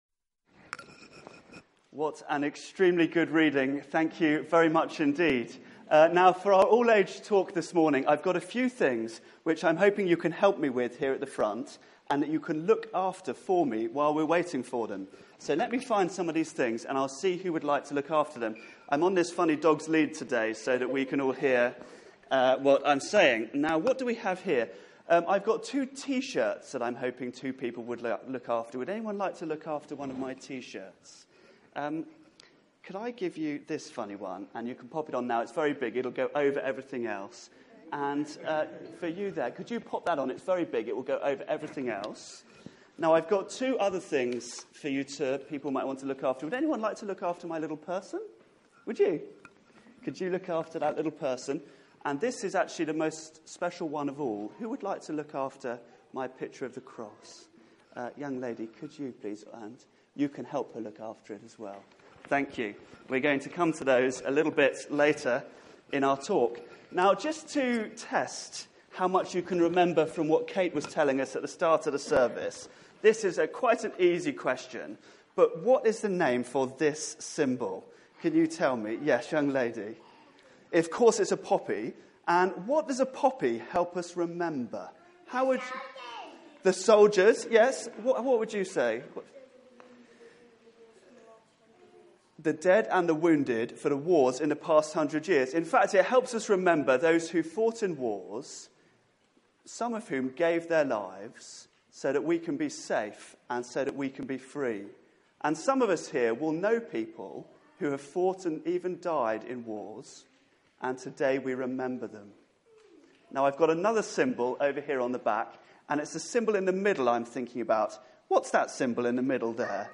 Media for 9:15am Service on Sun 13th Nov 2016 09:15 Speaker
Theme: Remembering the cross Sermon